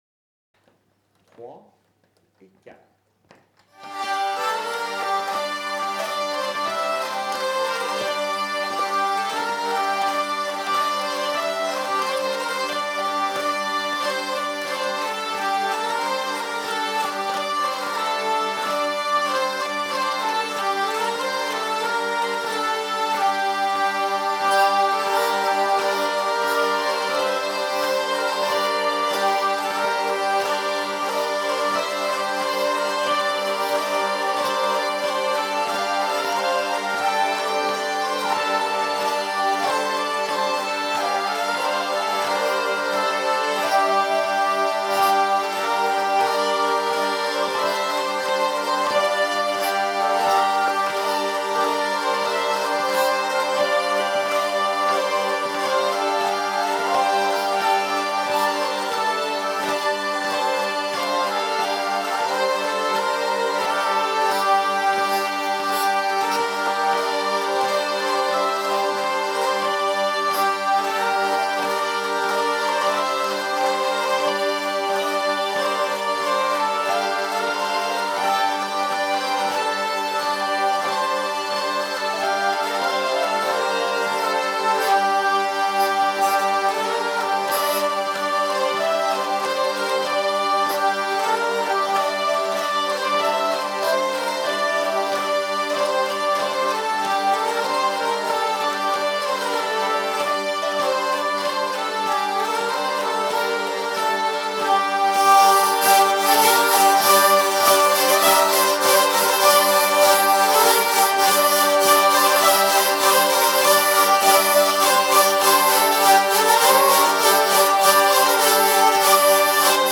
01_studio_vielles-marche.mp3